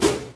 sounds_woosh_04.ogg